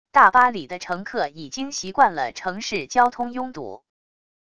大巴里的乘客已经习惯了城市交通拥堵wav音频生成系统WAV Audio Player